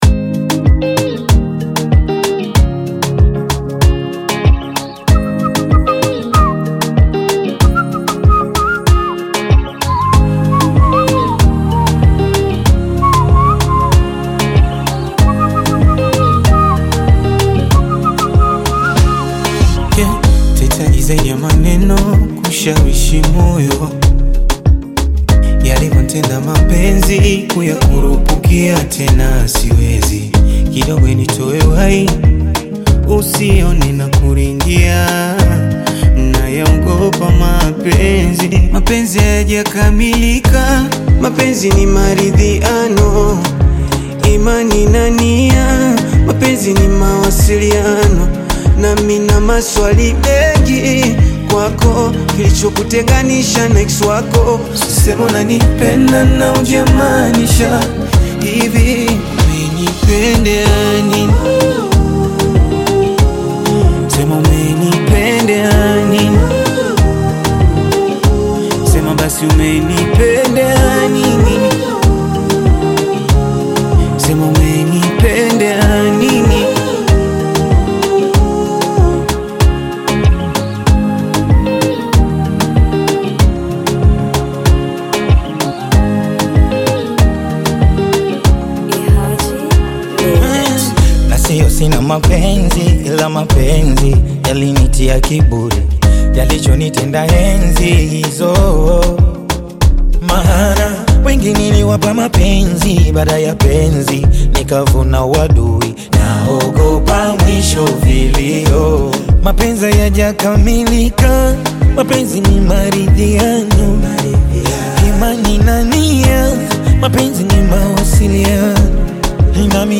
smooth vocals